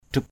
drup.mp3